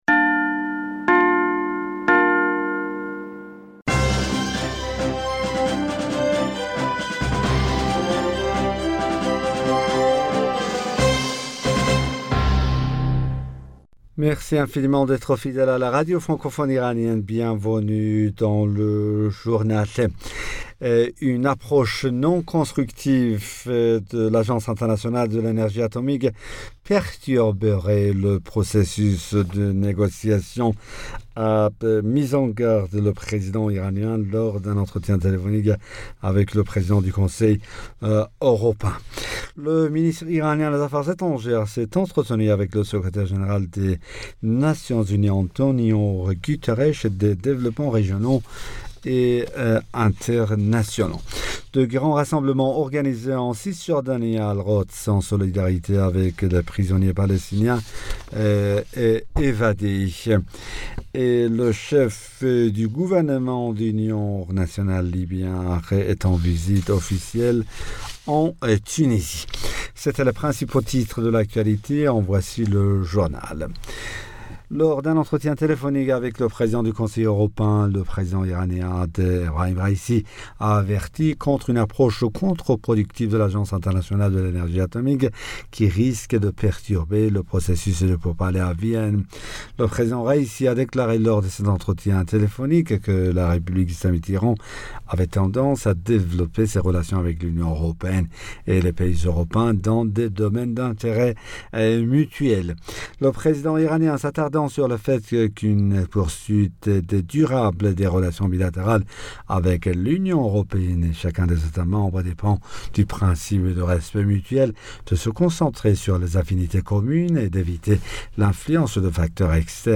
Bulletin d'information Du 10 September 2021